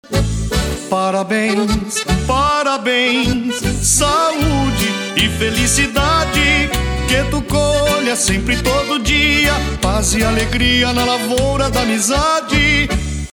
Refrão da música